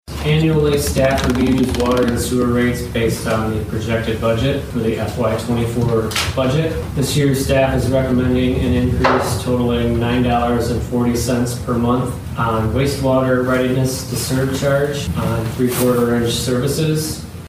City Manager Brandon Mersman shares the proposed rate for the upcoming year.